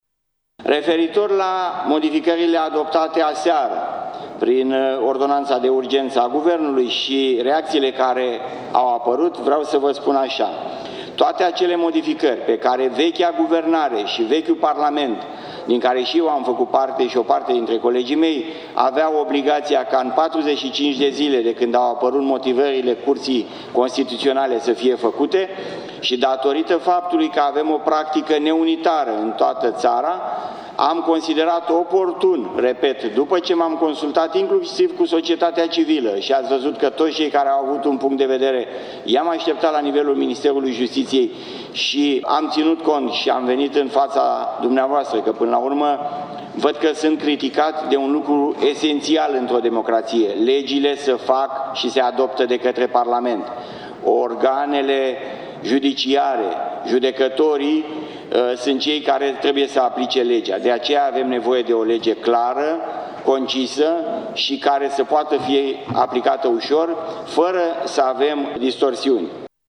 Declaraţiile ministrului Justiţiei, Florin Iordache, de la Palatul Parlamentului, au fost întrerupte de un grup de parlamentari USR care au protestat şi anterior la uşa grupului parlamentar al deputaţilor PSD.